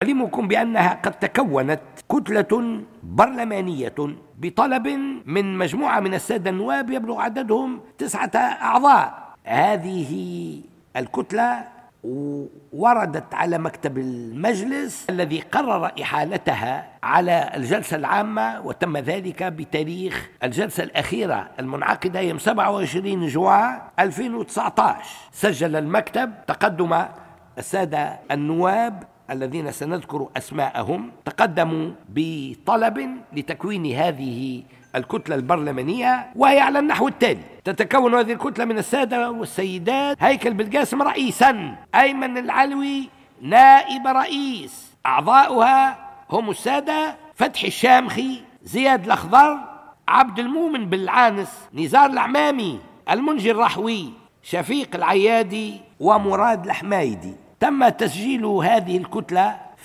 وأفاد مورو خلال جلسة بالبرلمان اليوم خُصصت لتوجيه أسئلة لوزير التجهيز أن الكتلة الجديدة تحمل اسم "كتلة الجبهة الشعبية" ،برئاسة النائب هيكل بلقاسم وتتكون من 9 نواب من المستقيلين من كتلة الجبهة الشعبية المنحلة.